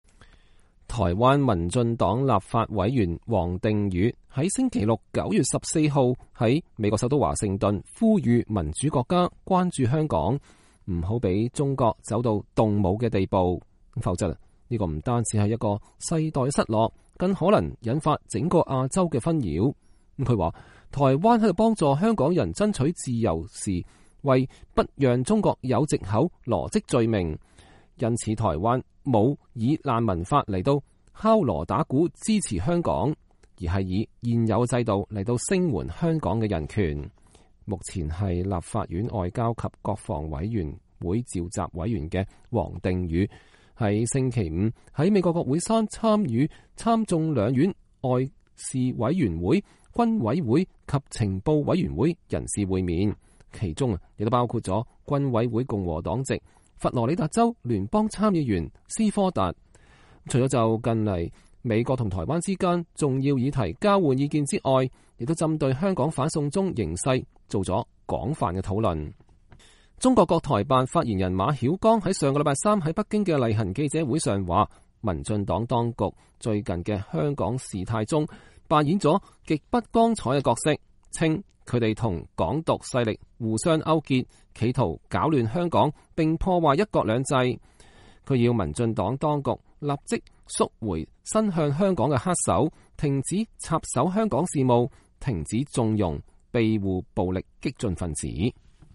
受邀在台灣人公共事務會 (FAPA) 2019年“國會倡議”年會發表演說的王定宇在演說前的記者會上表示，由於他在離開台北前曾經與香港眾志秘書長黃之鋒見面，他和美方針對如何協助香港一事提出台灣在的觀點。